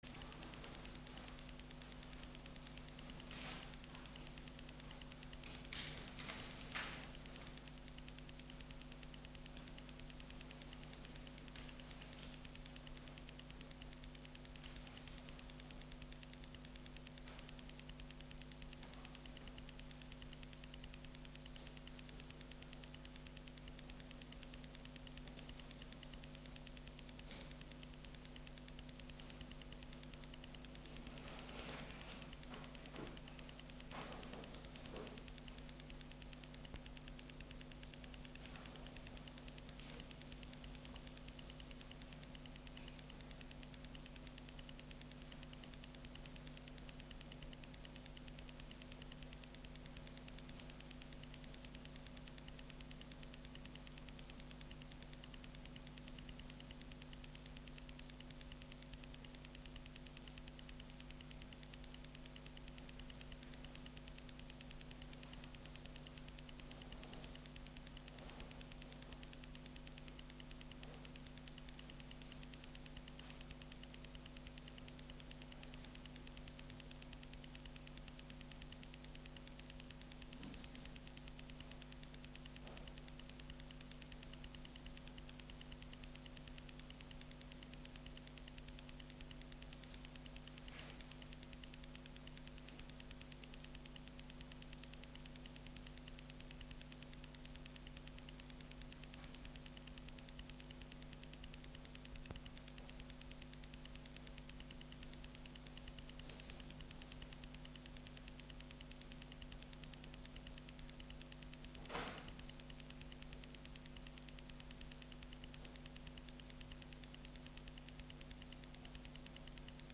Listen to our 1.30pm sermon here: